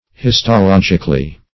-- His`to*log"ic*al*ly,